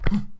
jump.wav